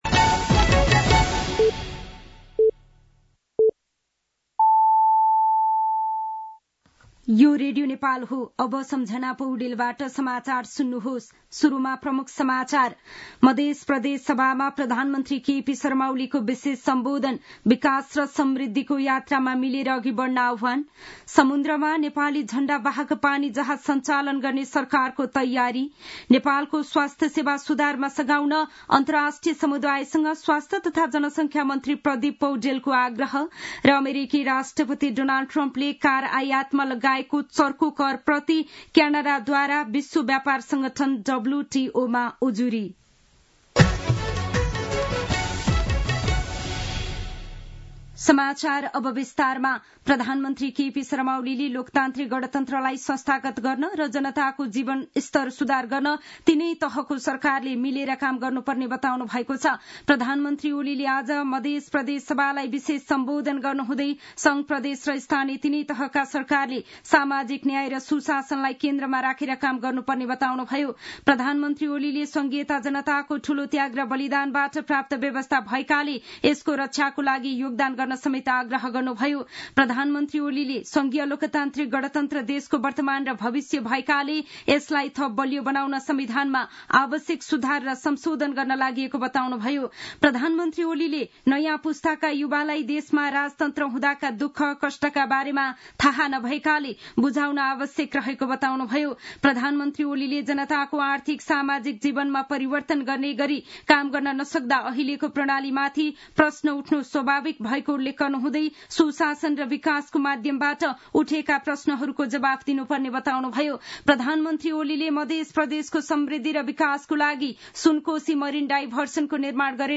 दिउँसो ३ बजेको नेपाली समाचार : २६ चैत , २०८१
3-pm-Nepali-News-12-26.mp3